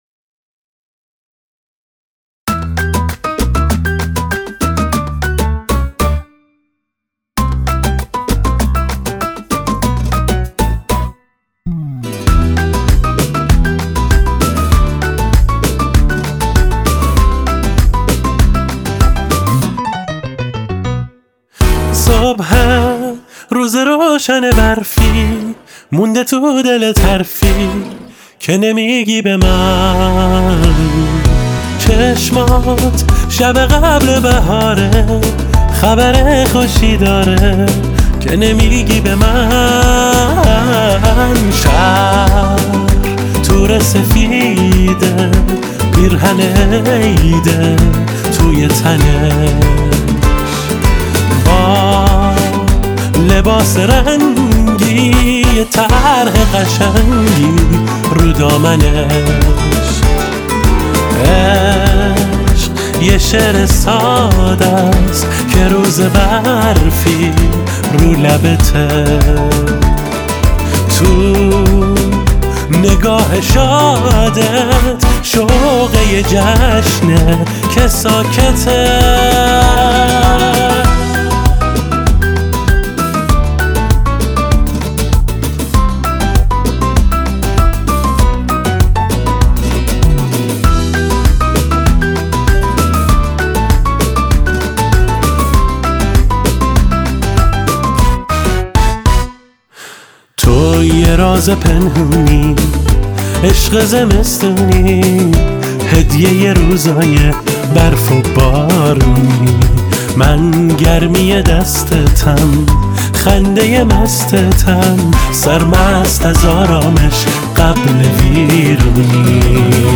با ریتم 4/4